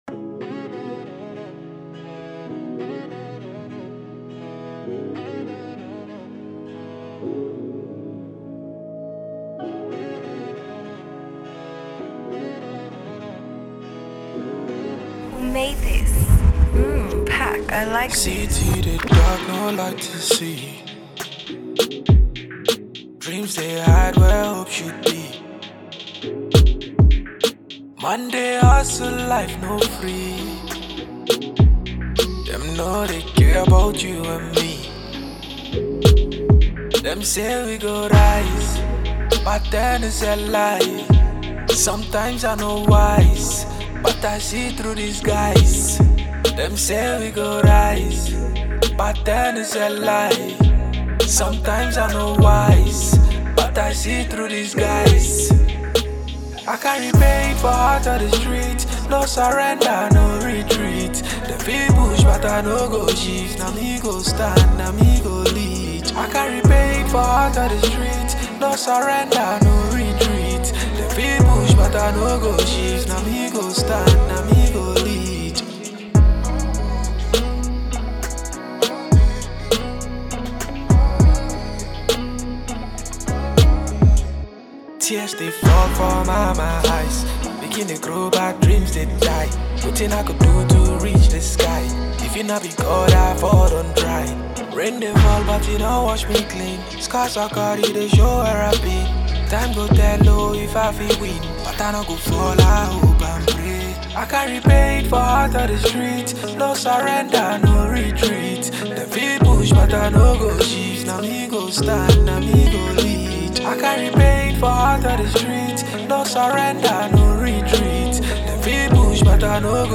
vocal delivery is calm yet heavy with emotion